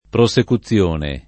[ pro S eku ZZL1 ne ]